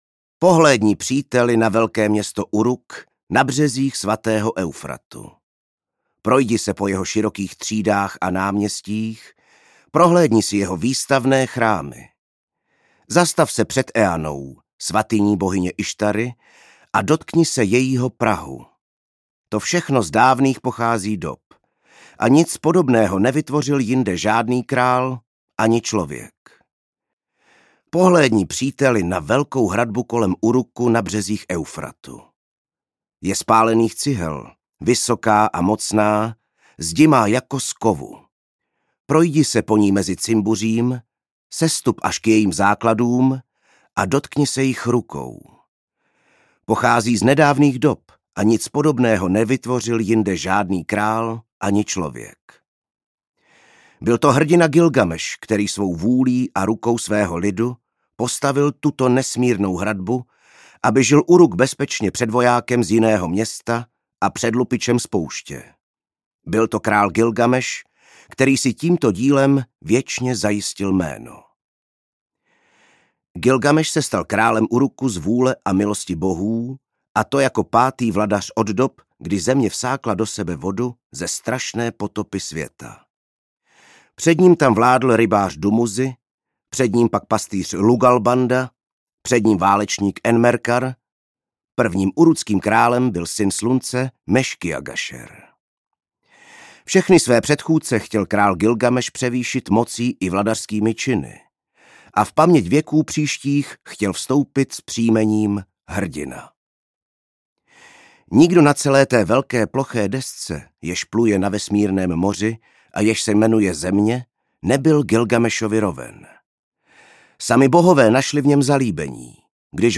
Gilgameš audiokniha
Ukázka z knihy
Vyrobilo studio Soundguru.